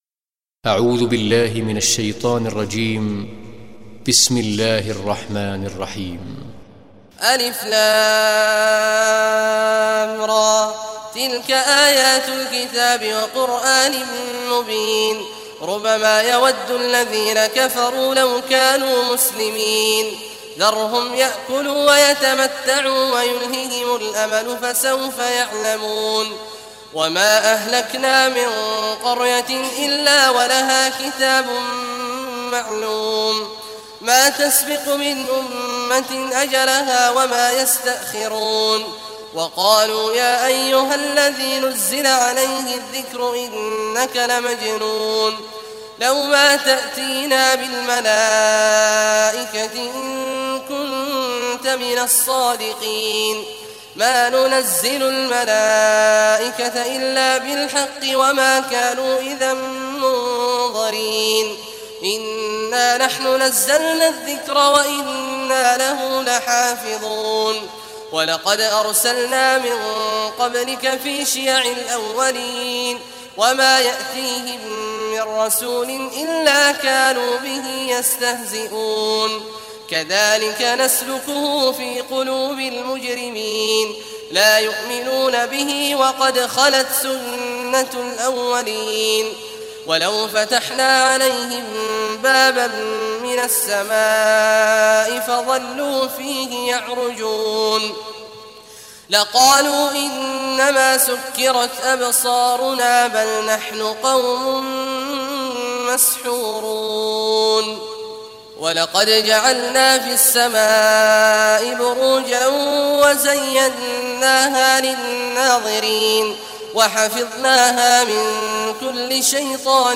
Surah al Hijr Recitation by Sheikh Abdullah Juhany
Surah Hijr, listen or play online mp3 tilawat / recitation in Arabic in the beautiful voice of Sheikh Abdullah Awad al Juhany.